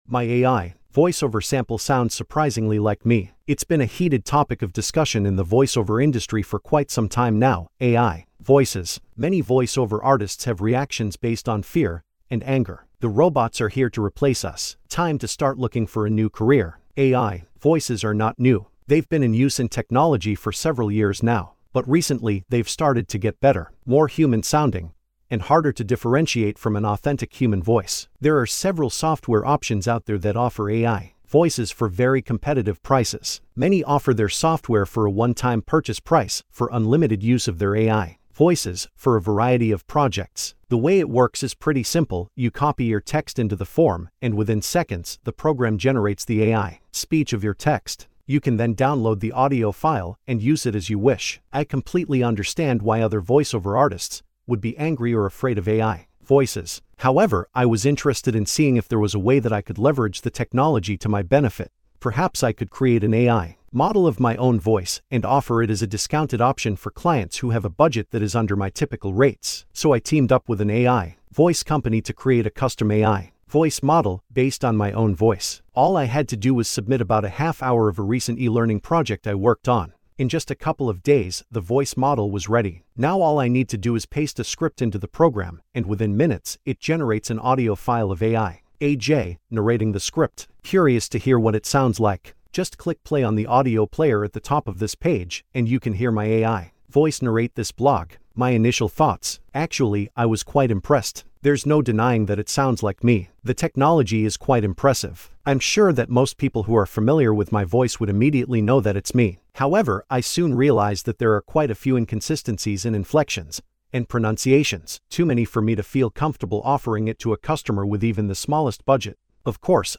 LISTEN TO THIS BLOG (NARRATED BY MY A.I. VOICEOVER MODEL) HERE